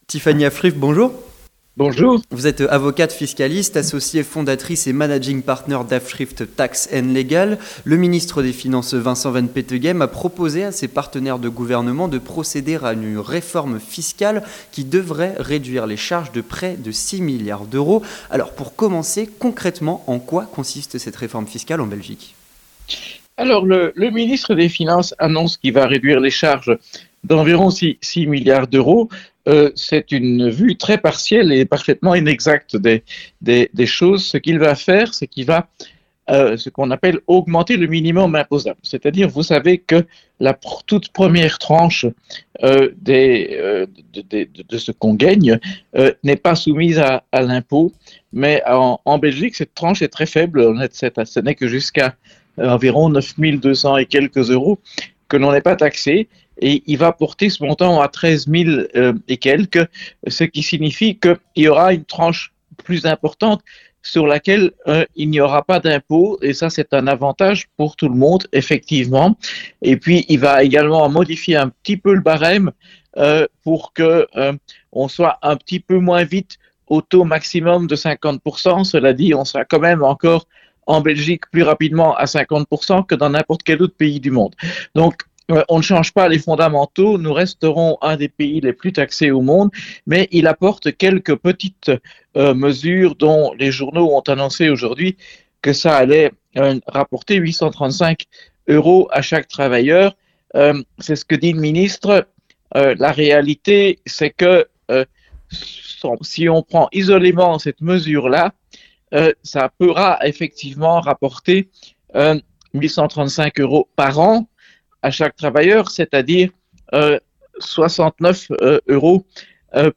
L'Entretien du Grand Journal - La nouvelle réforme fiscale belge
avocate fiscaliste